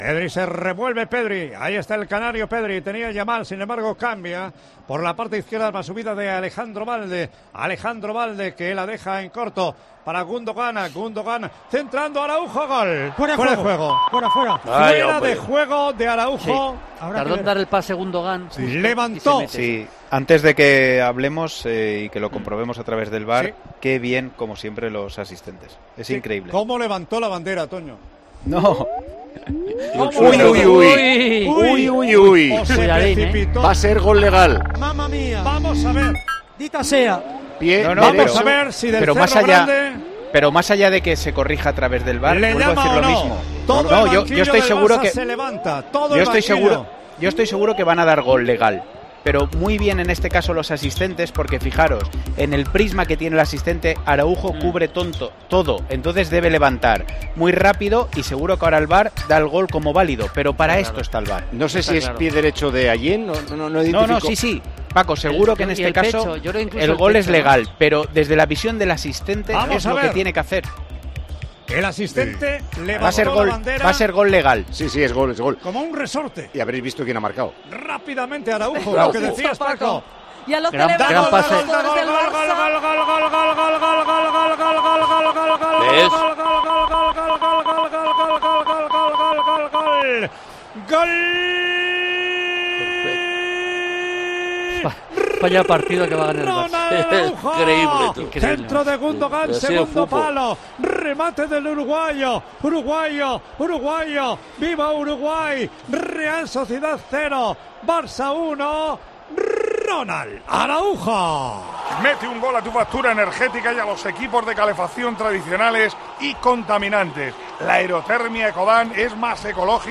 ASÍ TE HEMOS CONTADO EN TIEMPO DE JUEGO LA VICTORIA DEL BARCELONA EN SAN SEBASTIÁN
Con Paco González, Manolo Lama y Juanma Castaño